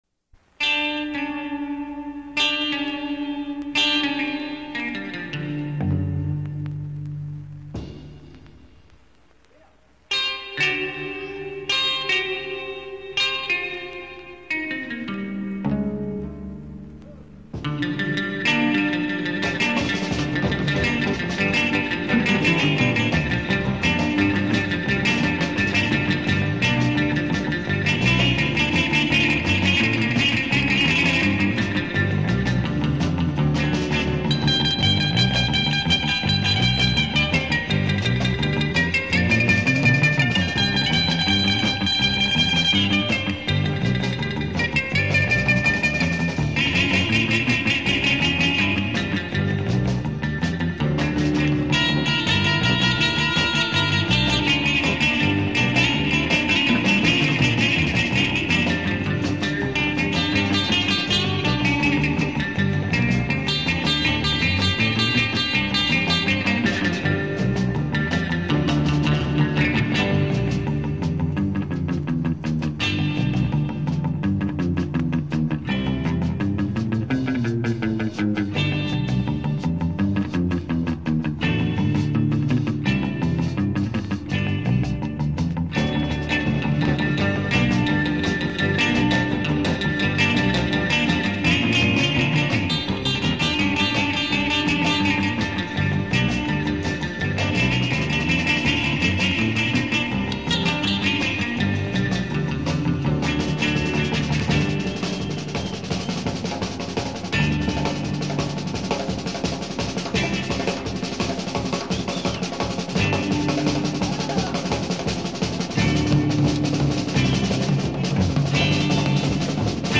lead guitar
drums
rhythm guitar
bass.